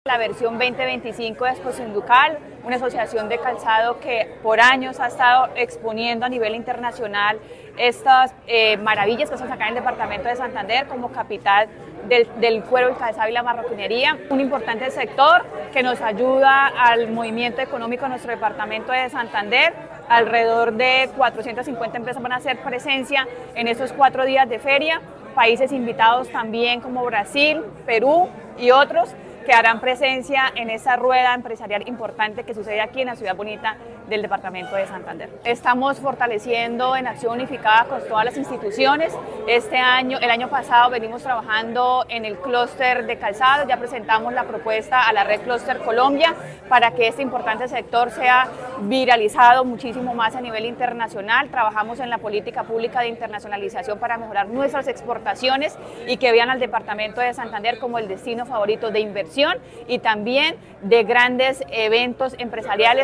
Eliana León, secretaria de competitividad de Santander